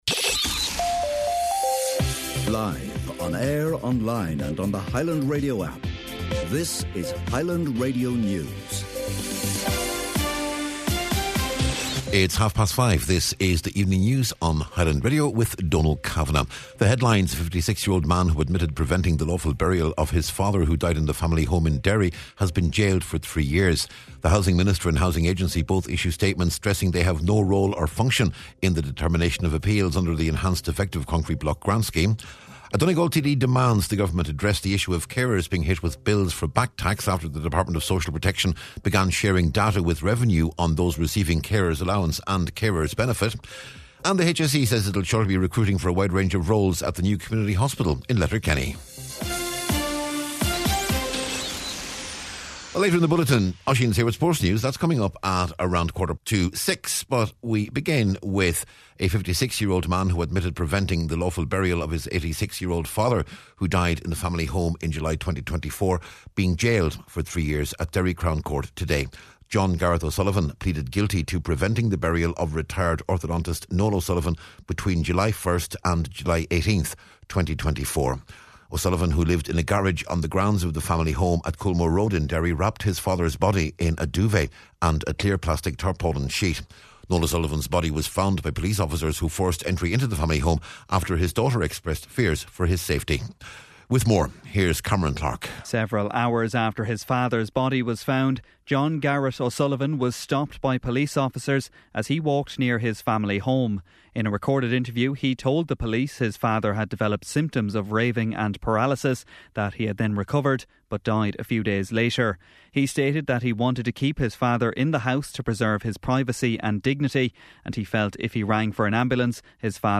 Evening News, Sport and Obituaries on Tuesday January 20th